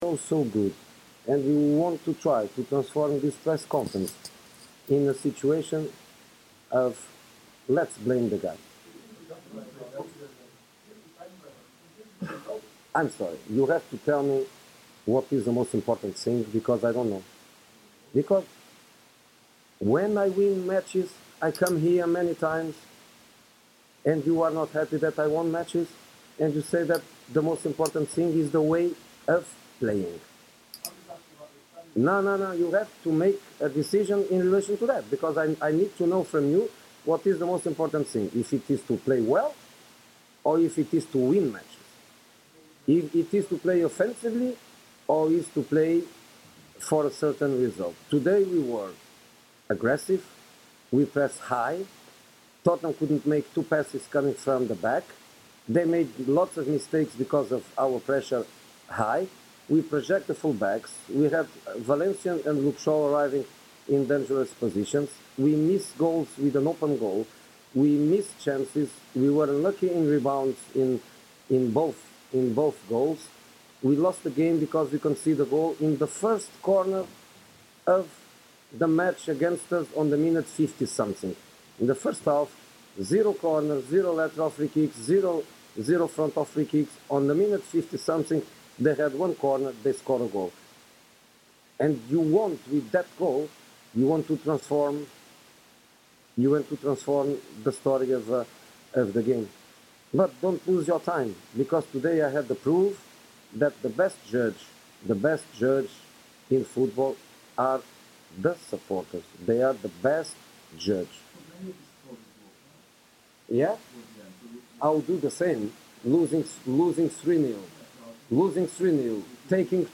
The independent, satirical Manchester United supporters' fanzine - for adults only, contains expletives - returns with waffle and bad sound. We're back sooner than ever before but we wanted to bring a bit of optimism into our lives.